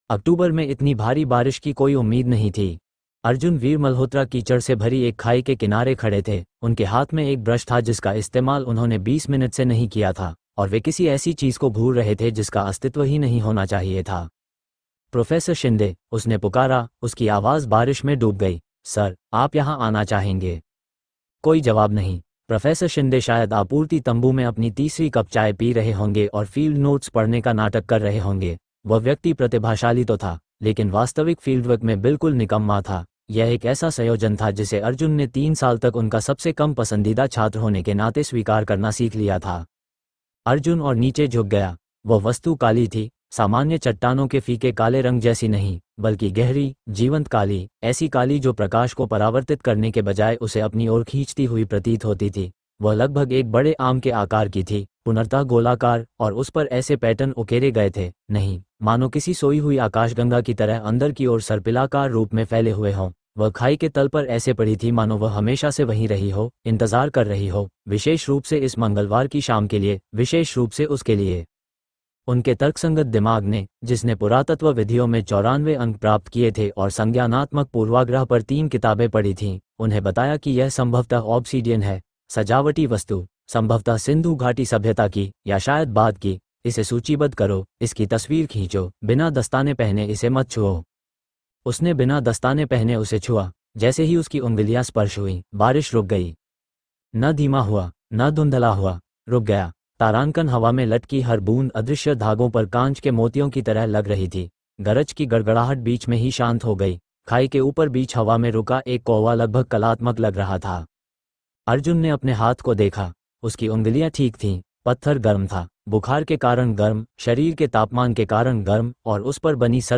“Shadow of Kali: The Chosen One” is a gripping dark fantasy audio series that follows a young man whose destiny is tied to seven ancient seals, each guarded by broken idols of Kali herself.
Cinematic audio experience